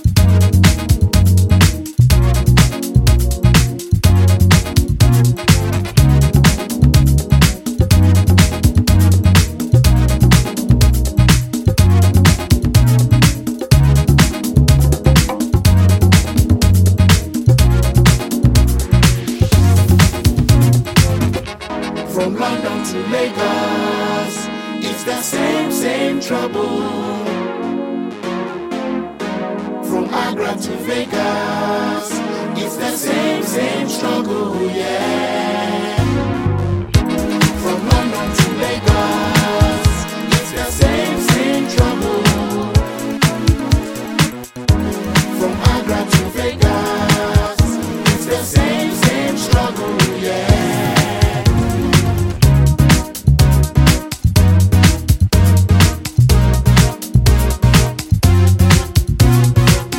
ジャンル(スタイル) SOULFUL HOUSE / AFRO HOUSE